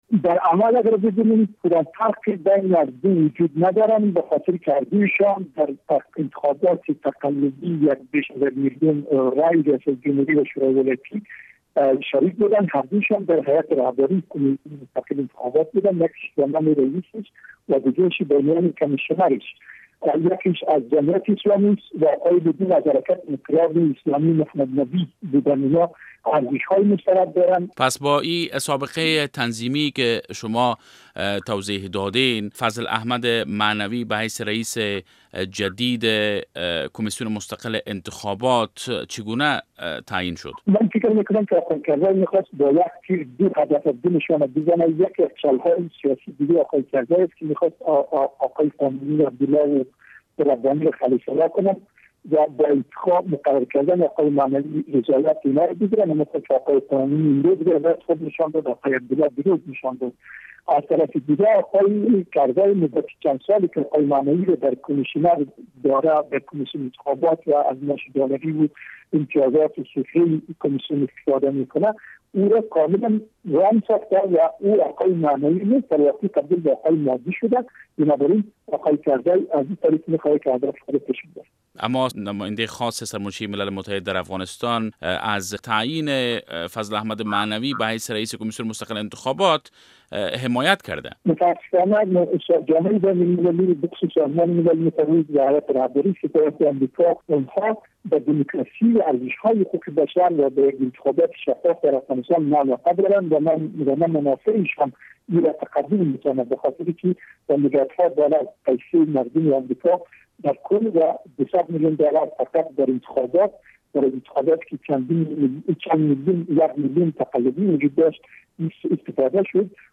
مصاحبه با رمضان بشردوست در مورد تقرر فضل احمد معنوی به حیث رییس جدید کمسیون مستقل انتخابات افغانستان